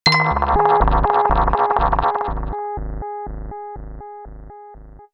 UI_SFX_Pack_61_59.wav